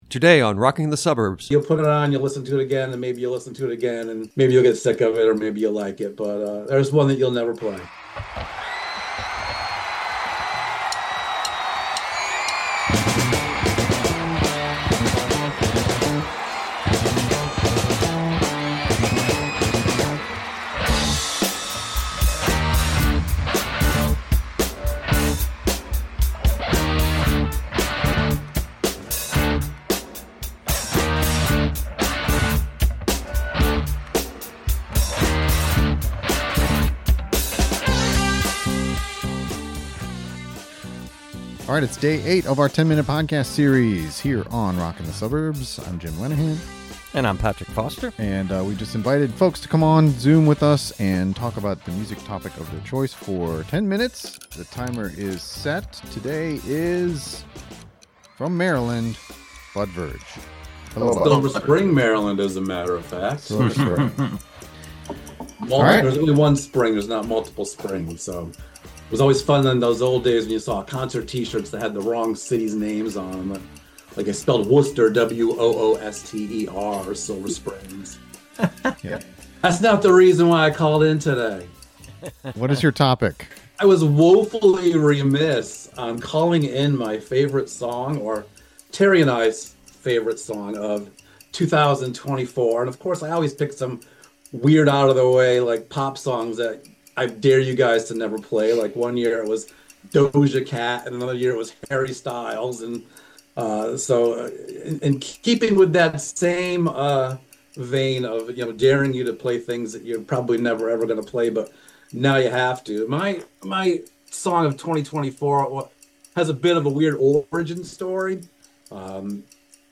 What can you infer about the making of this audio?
We recently held a virtual podcast recording where we invited participants to come up with a topic of their choice.